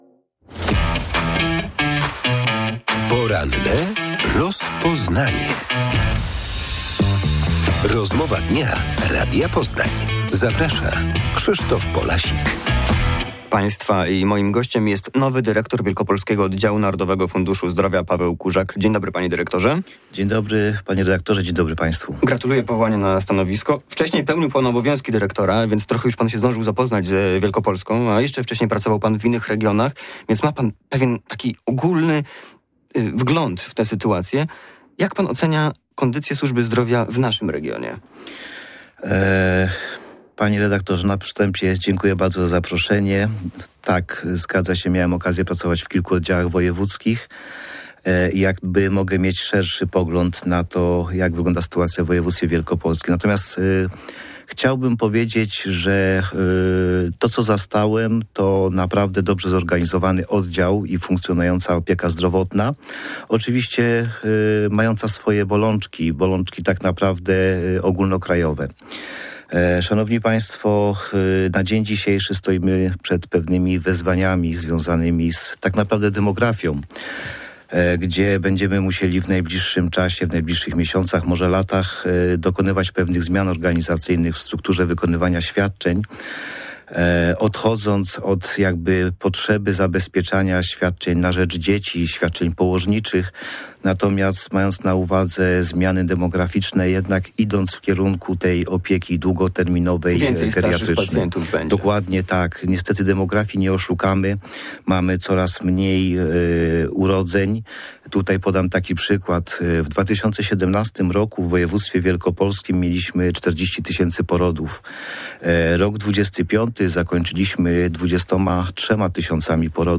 Nowy dyrektor wielkopolskiego oddziału NFZ Paweł Kurzak w Porannym Rozpoznaniu Radia Poznań odpowiadał na pytania o największe wyzwania przed służbą zdrowia w naszym województwie.
5uy3zwdqyuf139o_poranna_rozmowa_dyrektor__nfz.mp3